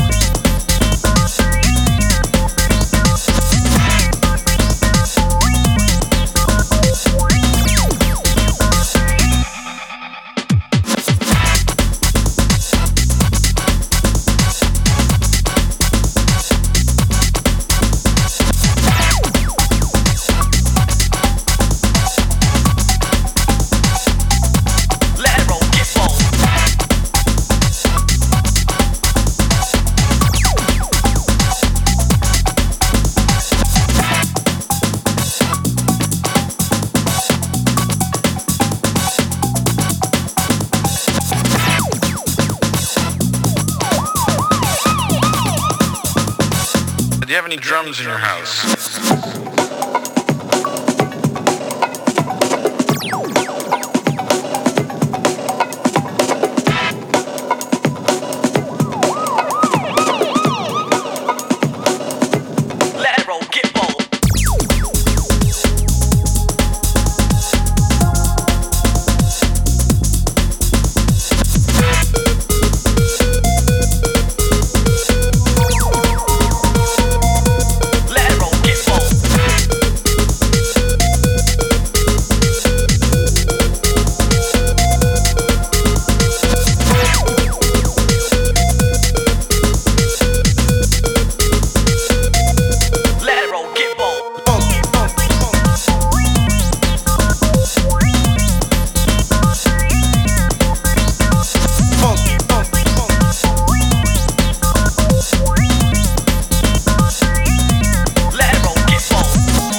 With a 90’s ravey
sizzling basslines and some great breaks